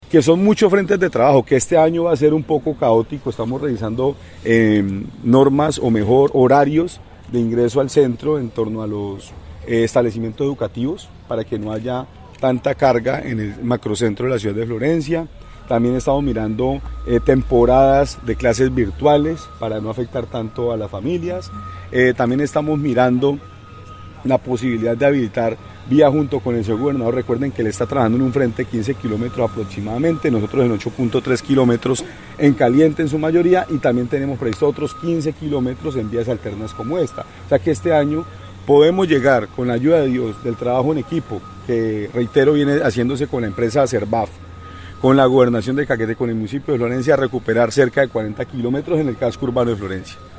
Marlon Monsalve Ascanio, alcalde del municipio de Florencia, explicó que, para garantizar el acceso a las sedes educativas se analizan varias opciones, como modificar los horarios de ingreso y salida, así, como la posibilidad de clases virtuales.